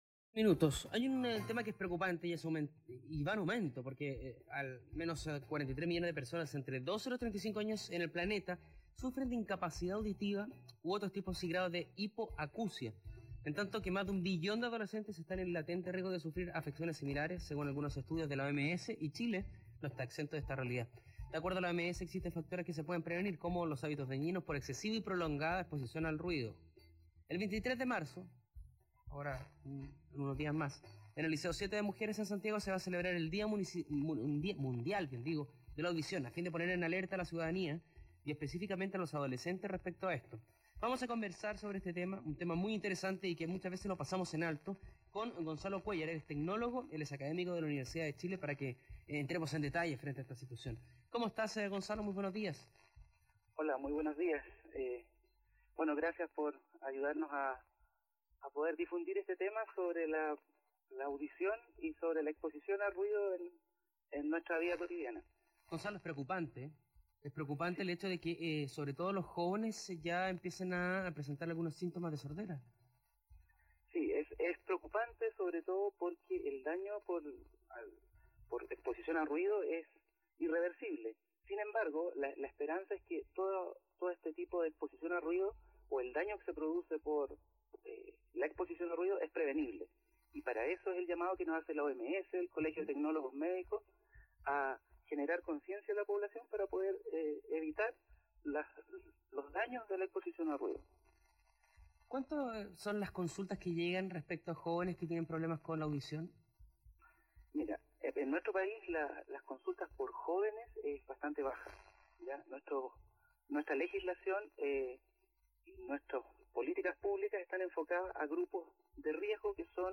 A continuación el audio de la entrevista.